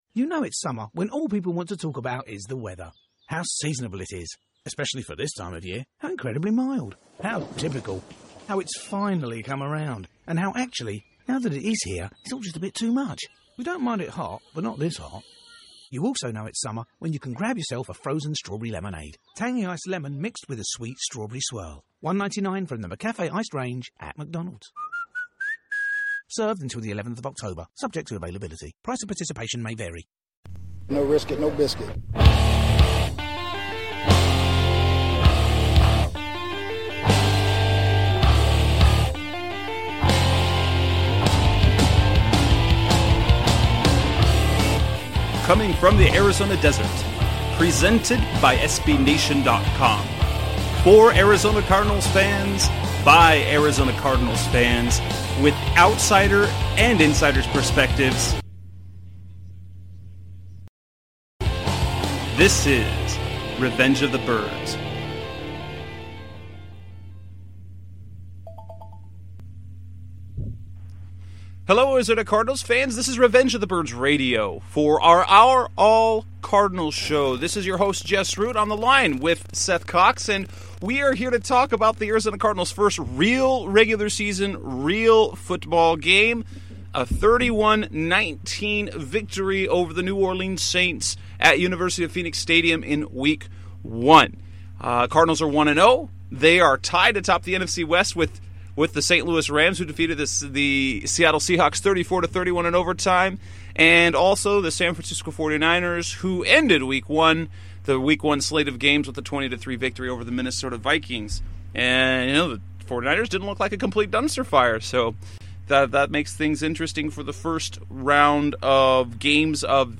They discuss the performance of Carson Palmer and the offense, as well as how the defense performed.